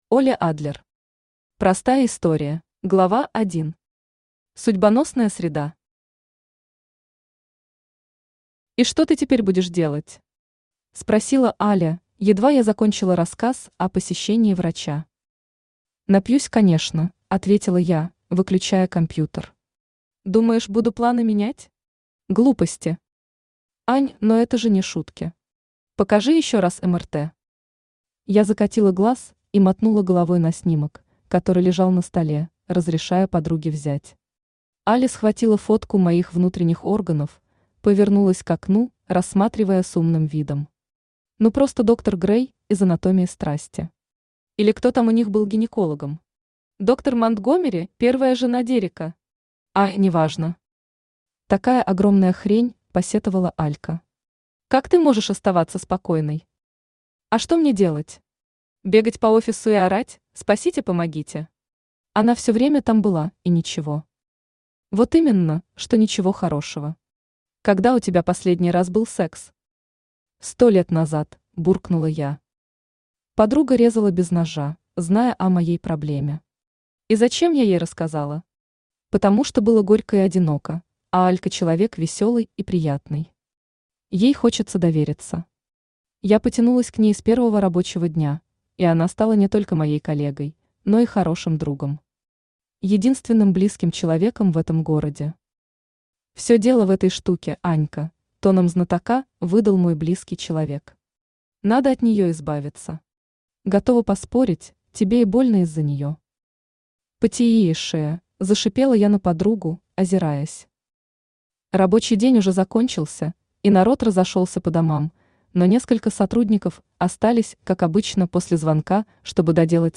Aудиокнига Простая история Автор Оле Адлер Читает аудиокнигу Авточтец ЛитРес.